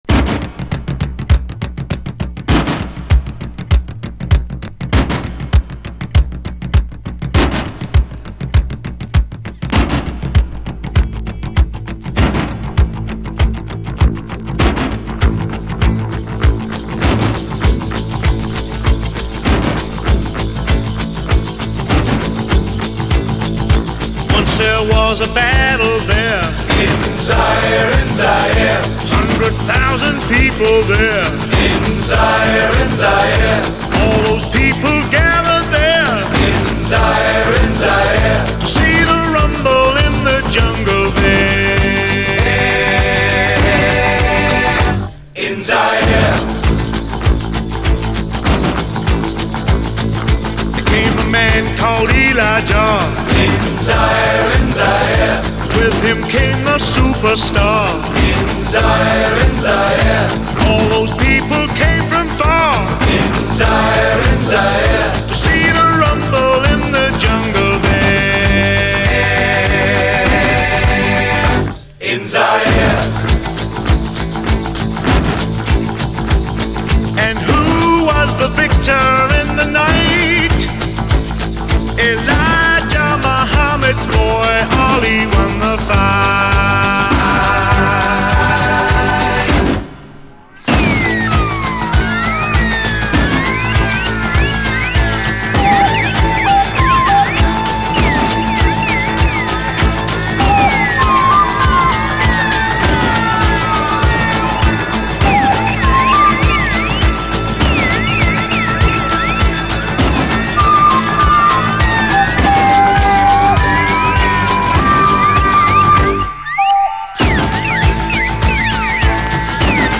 SOUL, 70's～ SOUL, DISCO, AFRO, 70's ROCK, WORLD, ROCK, 7INCH